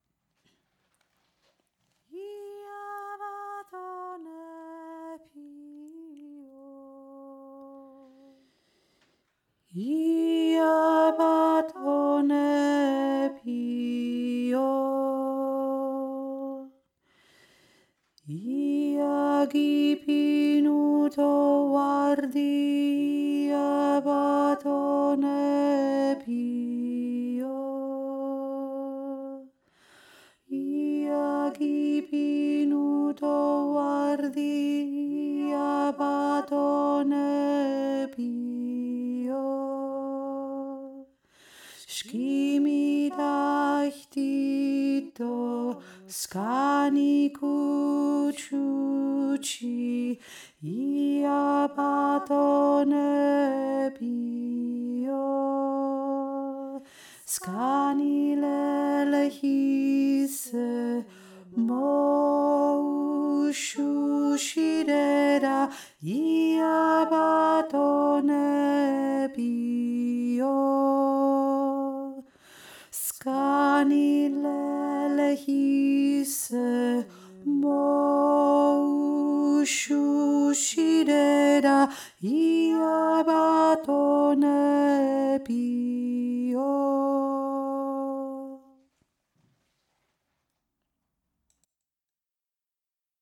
Georgisches Heilungslied
mittlere Stimme
ia-batonepi-o-mittlere-stimme.mp3